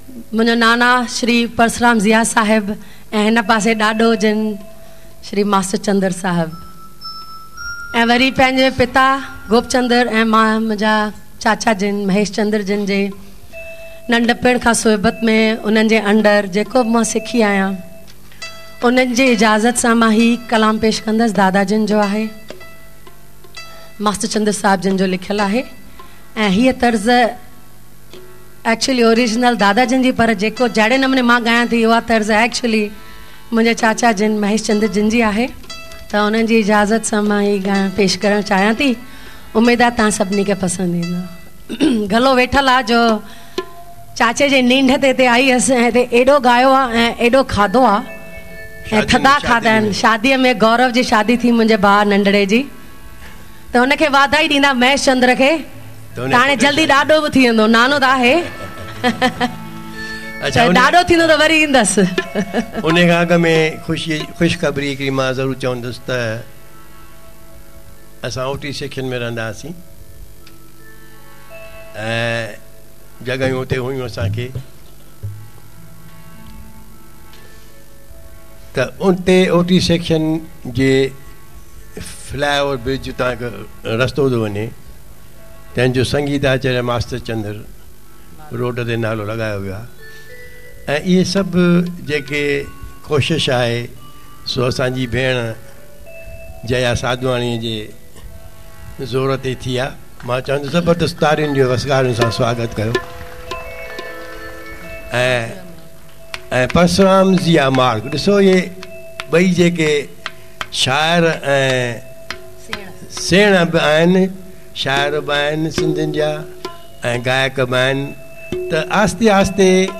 Format: LIVE
Live Performance